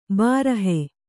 ♪ bārahe